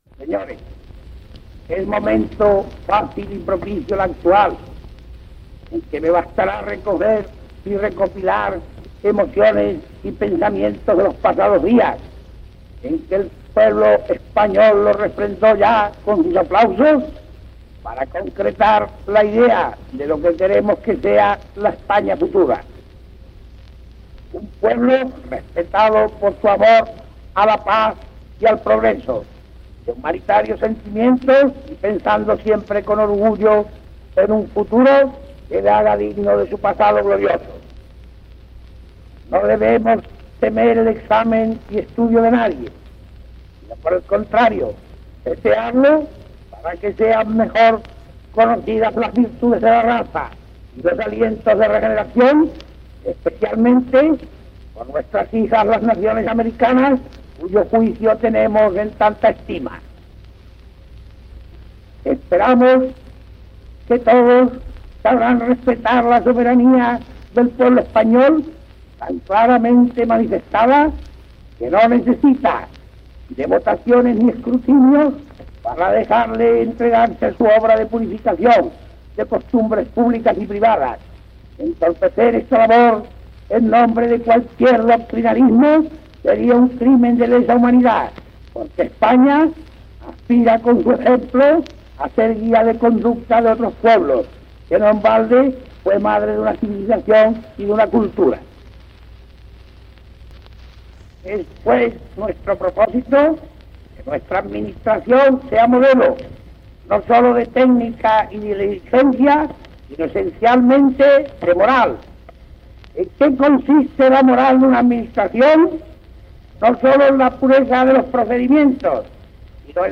Definició de la Unión Patriótica per Miguel Primo de Rivera, Marqués de Estella, president del Directorio Militar
Informatiu
Inclòs en el "Catálogo de discos de 78 rpm en la Biblioteca Nacional.", impressionat a Madrid pel Ministerio de la Guerra, el 30 de gener de 1925.